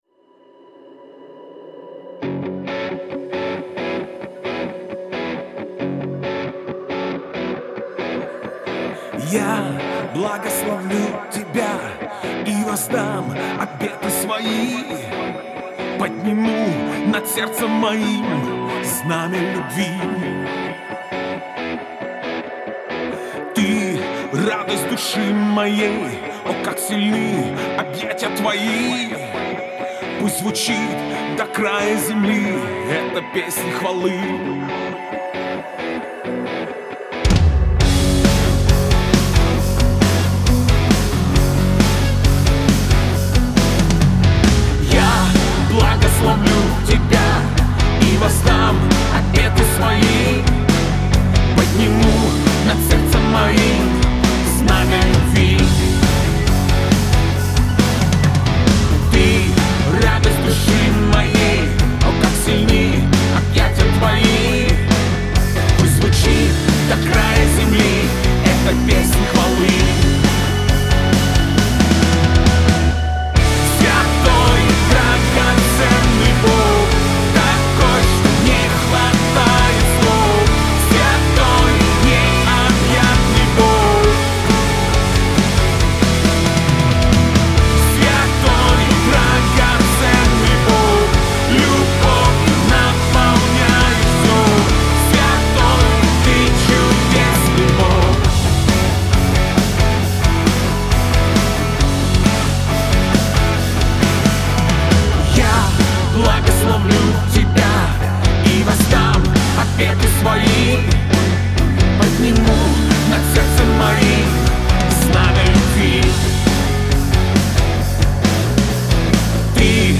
песня
2505 просмотров 1727 прослушиваний 194 скачивания BPM: 135